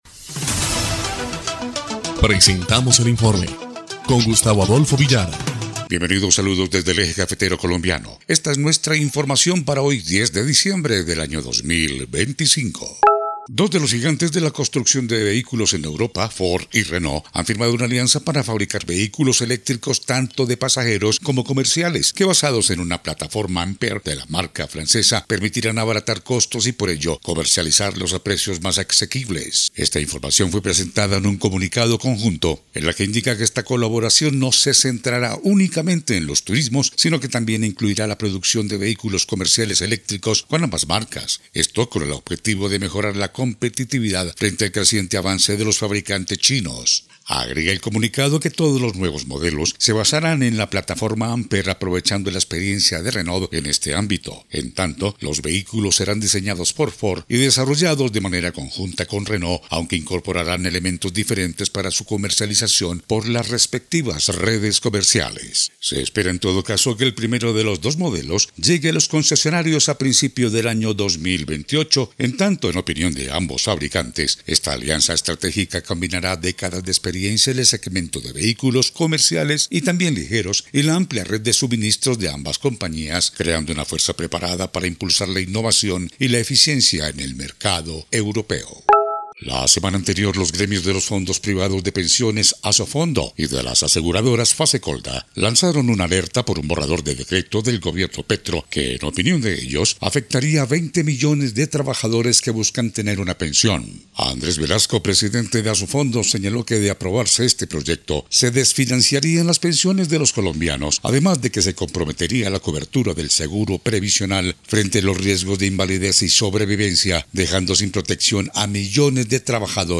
EL INFORME 1° Clip de Noticias del 10 de diciembre de 2025